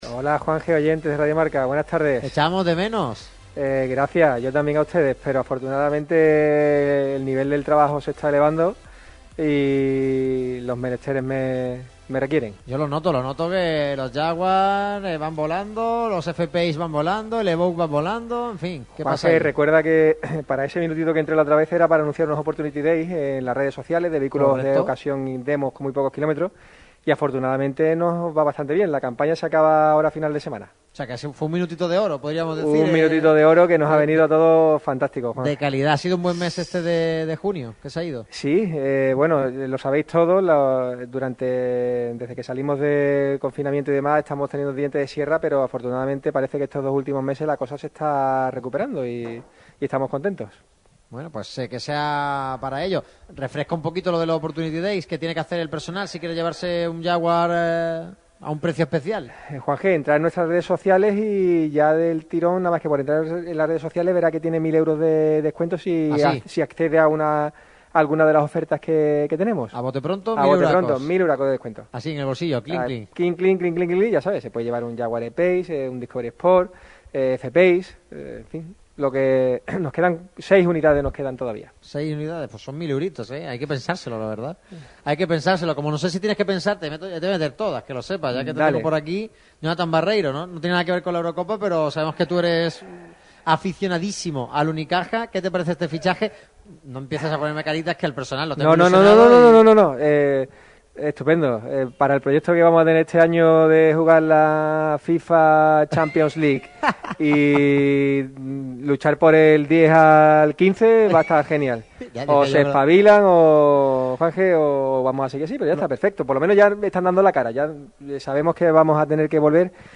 La radio que vive el deporte se desplaza hasta las instalaciones para emitir su programa